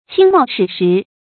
親冒矢石 注音： ㄑㄧㄣ ㄇㄠˋ ㄕㄧˇ ㄕㄧˊ 讀音讀法： 意思解釋： 見「親當矢石」。